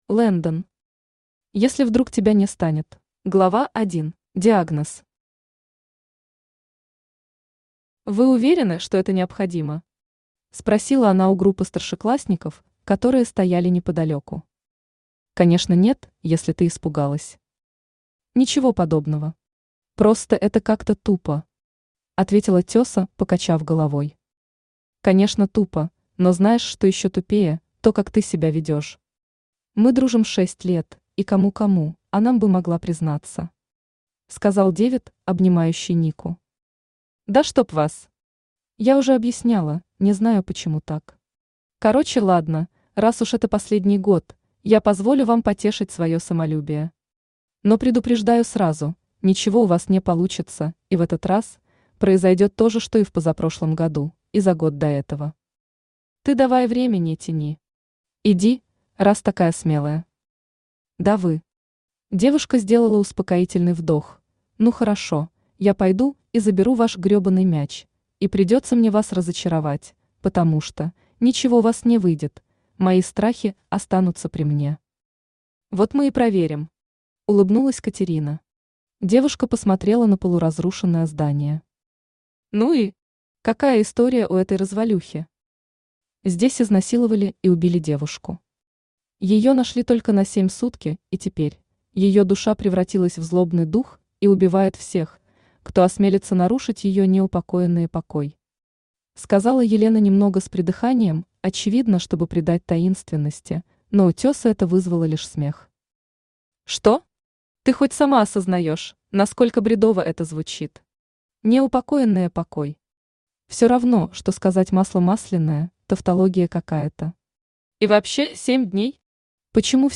Aудиокнига Если вдруг тебя не станет Автор Landen Читает аудиокнигу Авточтец ЛитРес.